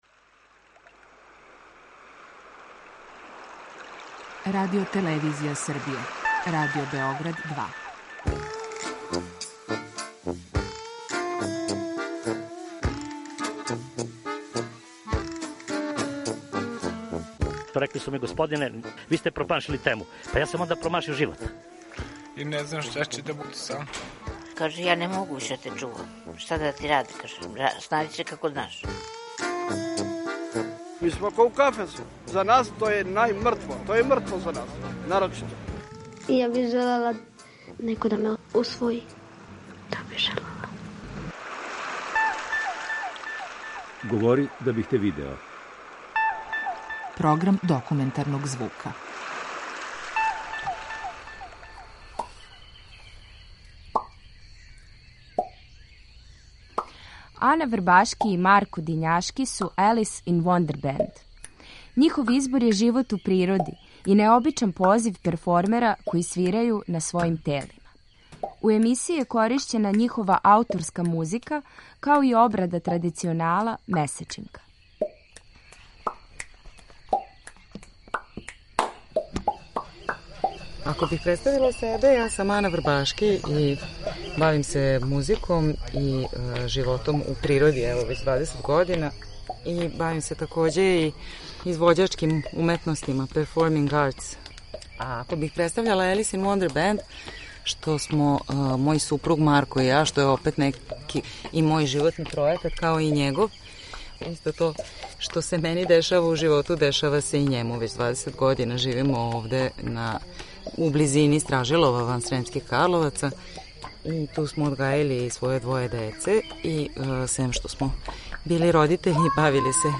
Документарни програм
Њихов избор је живот у природи и необичан позив перформера који свирају на својим телима. У емисији је коришћена њихова ауторска музика, као и обрада традиционала „Месечинка".